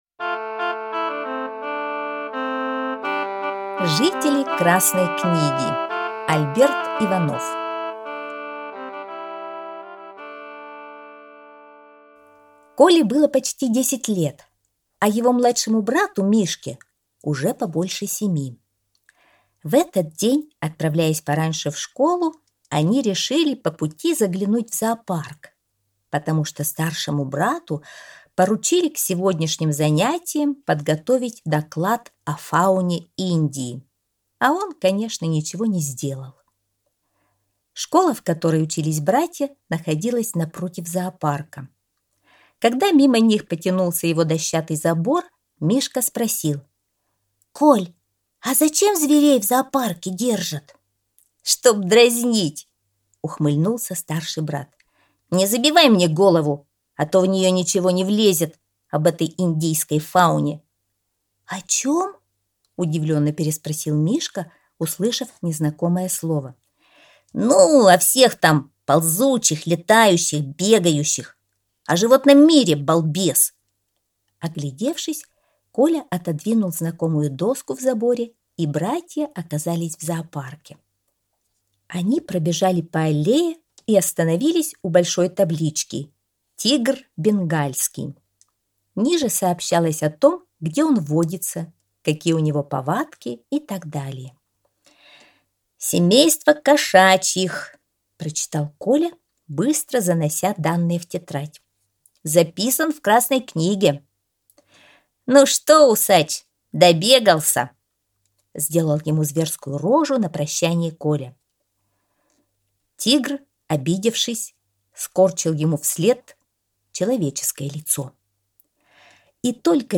Жители Красной книги - аудиосказка Альберта Иванова - слушать онлайн